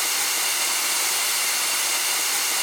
steam_loop.wav